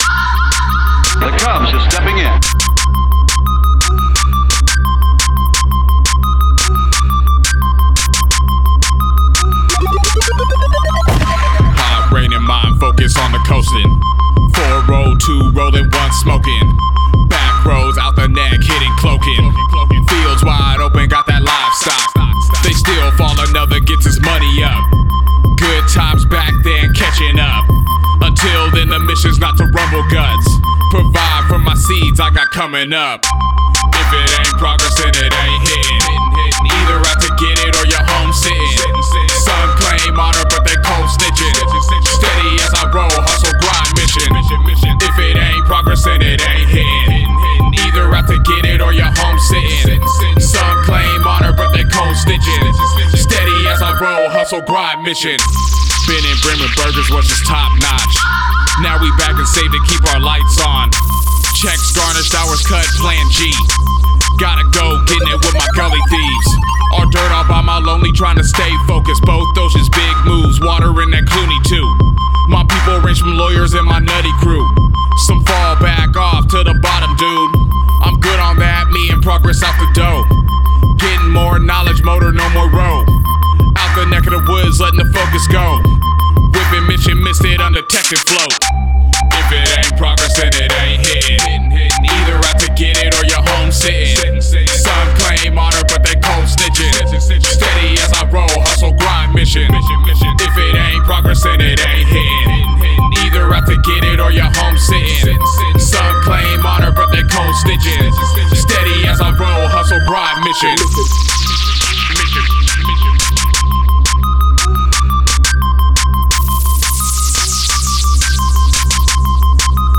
Recorded at AD1 Studios, Seattle Washington